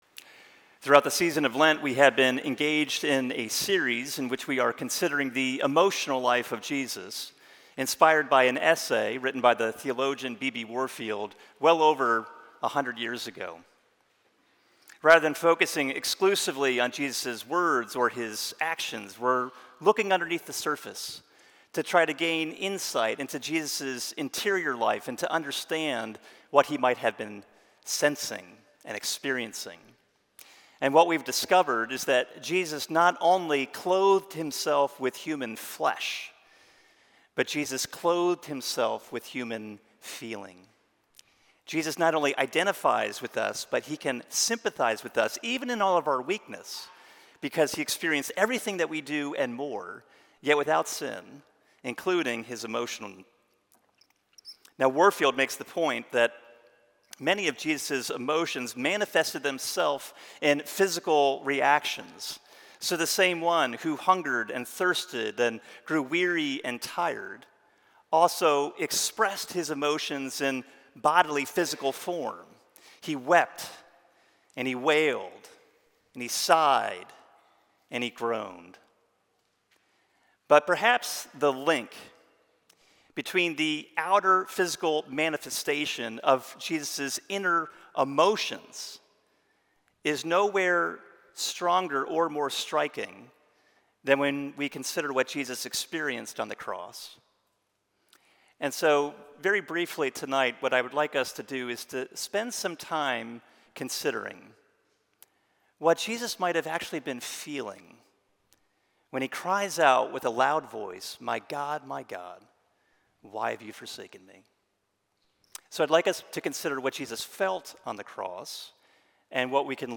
Watch this sermon as we consider how Jesus’ emotions on the cross demonstrate his salvation — and his utmost love for us.